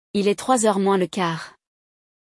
No episódio de hoje, vamos acompanhar um diálogo que acontece bem tarde da noite, ou melhor, às duas da manhã.